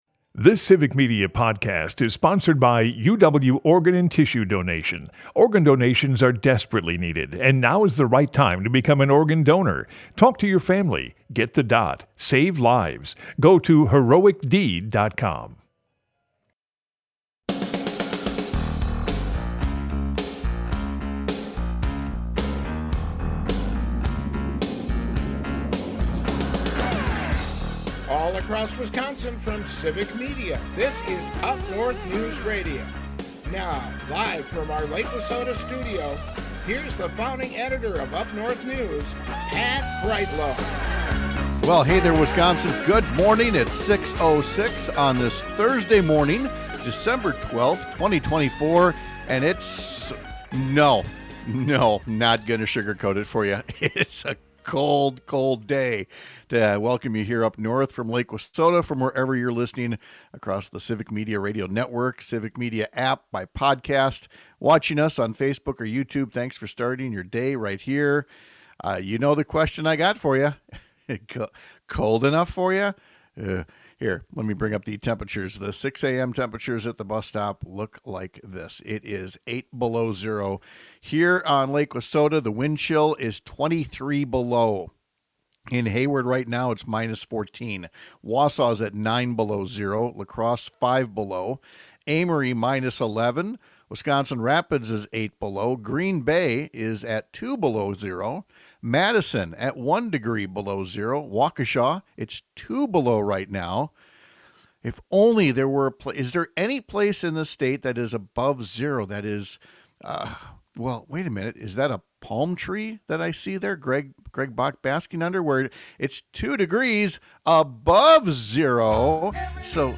Pat Kreitlow is a longtime Wisconsin journalist and former state legislator who lives in and produces his show from along Lake Wissota in Chippewa Falls. UpNorthNews is Northern Wisconsin's home for informative stories and fact-based conversations. Broadcasts live 6 - 8 a.m. across the state!